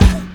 1 Foyer Cough.wav